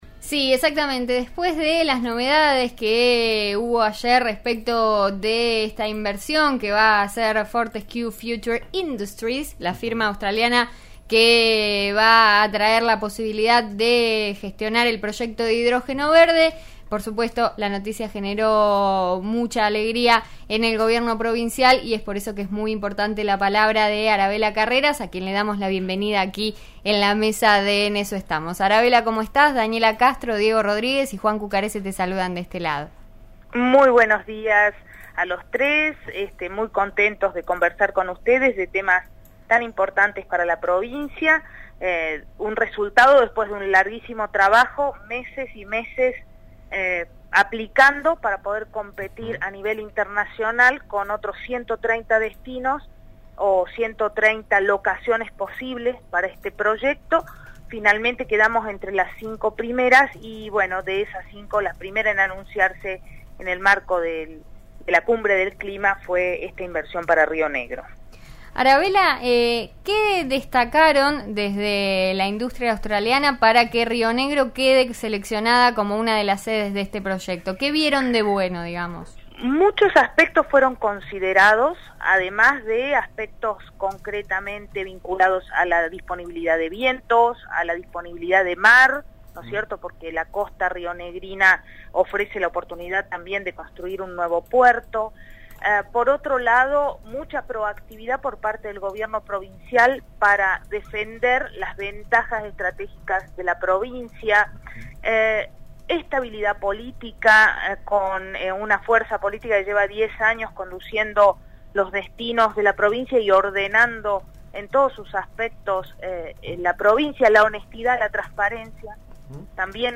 La gobernadora adelantó en RN RADIO que la empresa se comprometió a capacitar a los trabajadores.
La gobernadora de Río Negro Arabela Carreras brindó detalles, esta mañana, sobre la llegada de la millonaria inversión de proyecto de hidrógeno verde en la provincia.